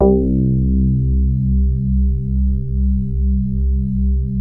JAZZ SOFT C1.wav